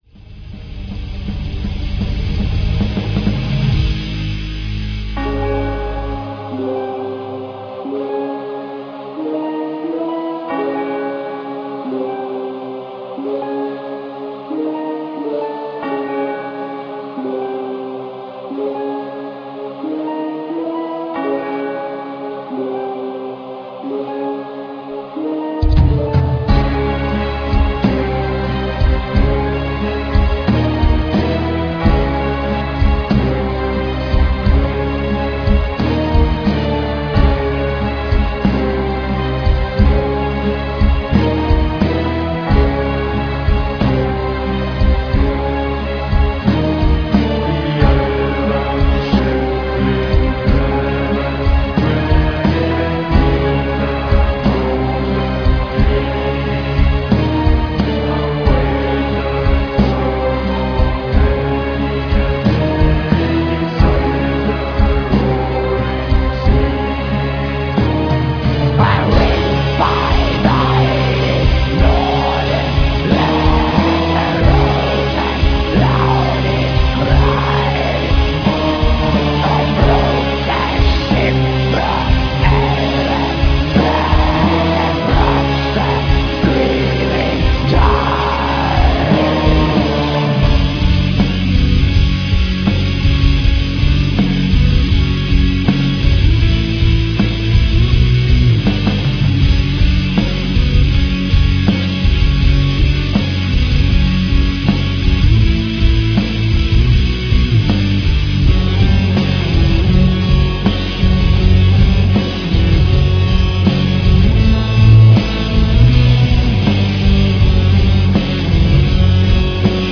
která hraje docela dobře znějící melodický doom-black.
(doom metal)